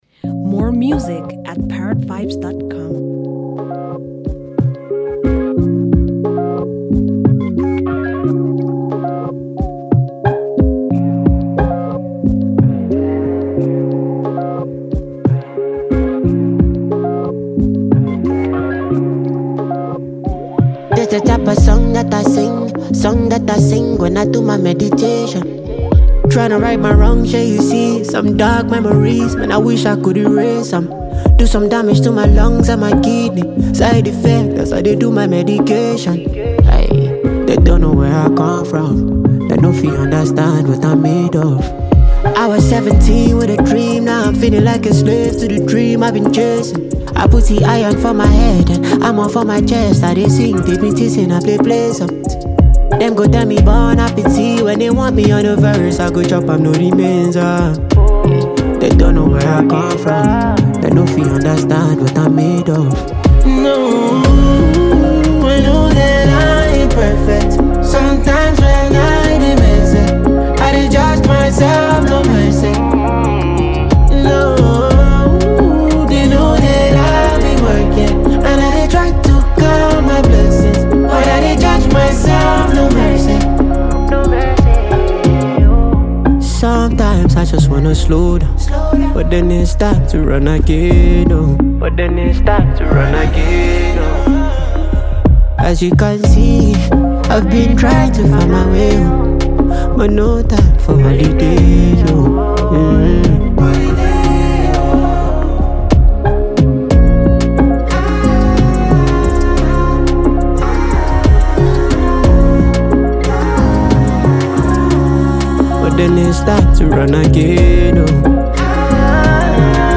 enchanting song